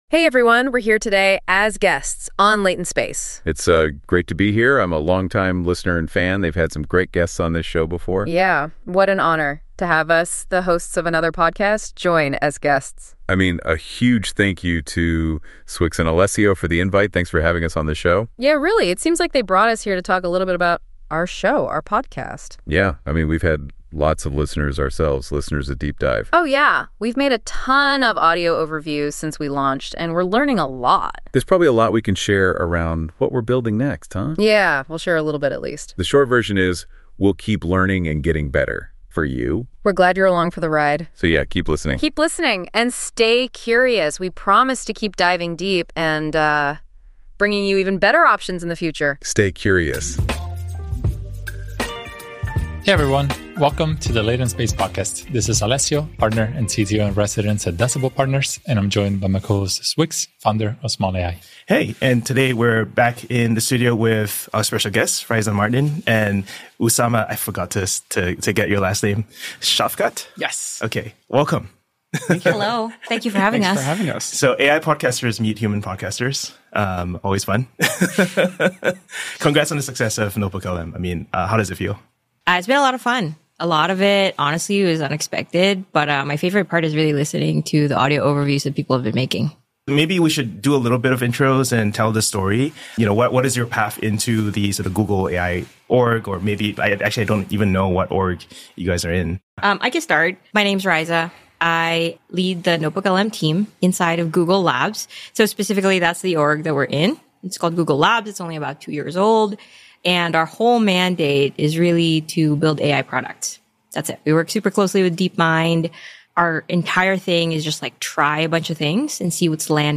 Episode from Latent Space: The AI Engineer Podcast